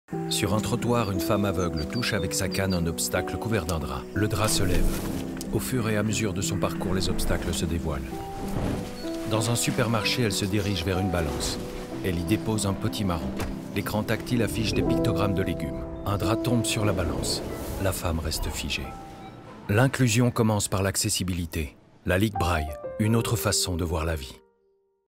Voix off Ligue braille
30 - 45 ans - Baryton